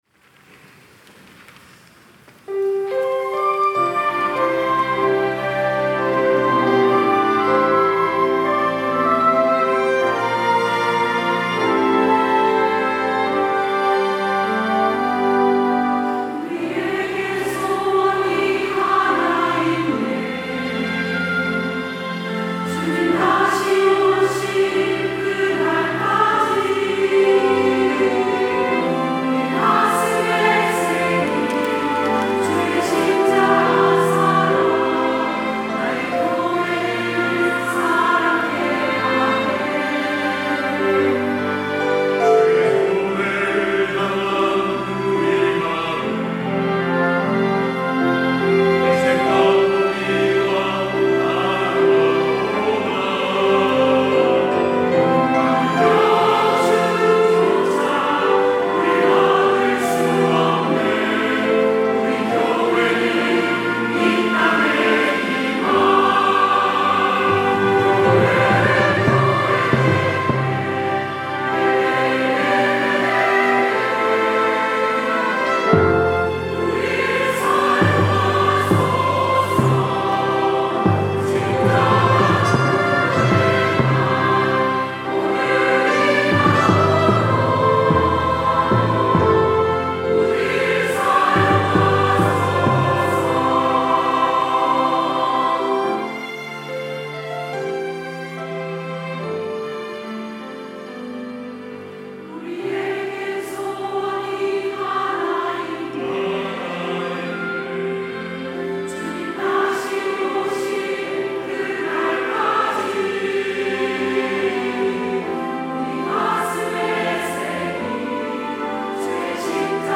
특송과 특주 - 우릴 사용하소서
시니어, 가브리엘, 호산나 찬양대